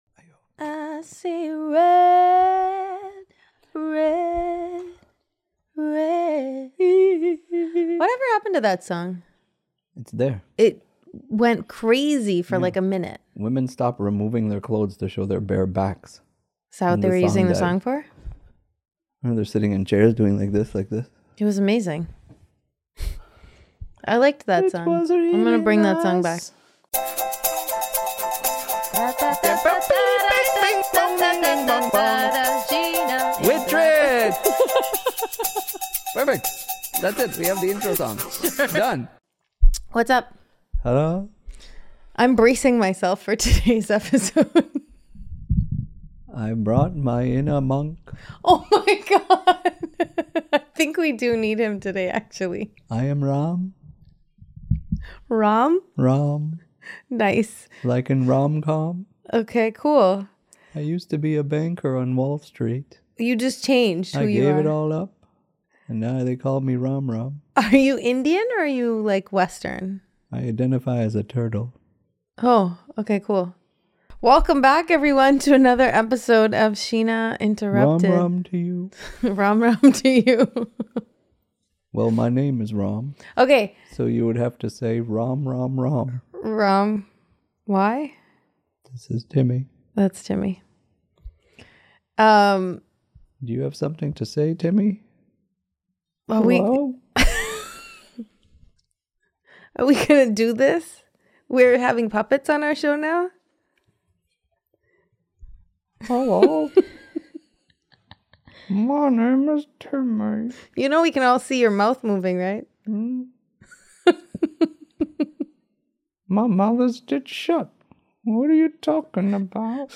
No scripts.
Just two people who tell it like it is, laugh when they shouldn't, and somehow make you feel less alone every single week.